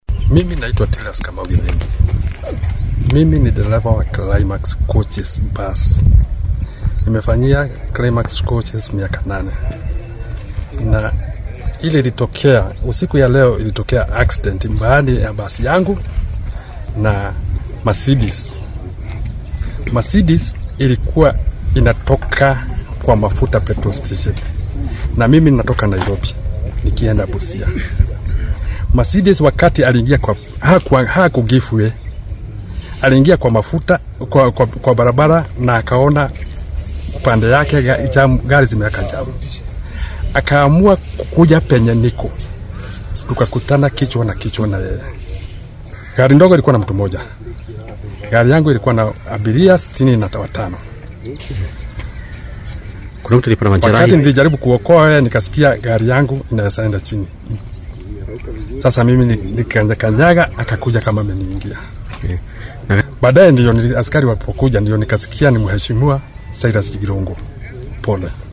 darawalka-Baaska-1.mp3